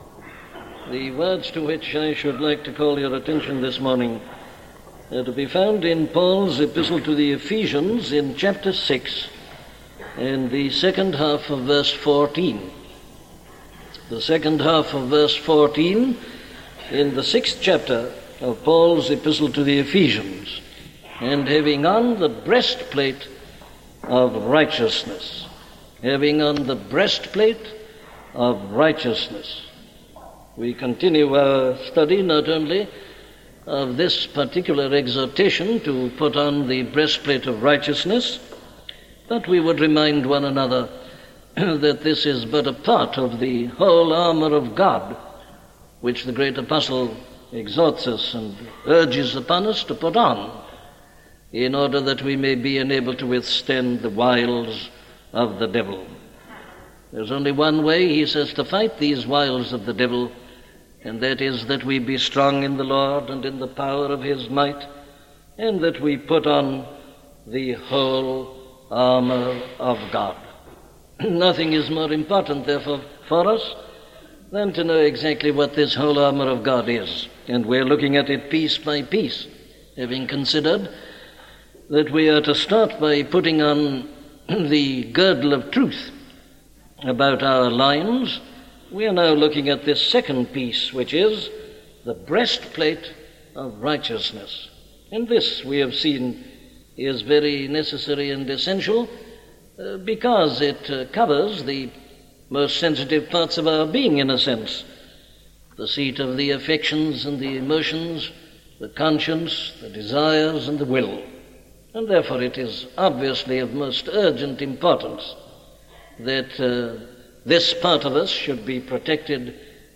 Looking Unto Jesus - a sermon from Dr. Martyn Lloyd Jones